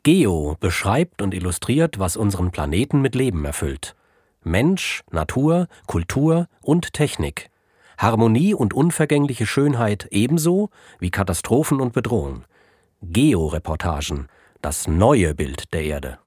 Deutscher Sprecher, tief, Kinderbuch, Sachtext, Voice-Over, Lyrik, Roman, Vorleser Arne Dahl, MP3-Home-Production (ZOOM), 6 deutsche Dialekte
Sprechprobe: Werbung (Muttersprache):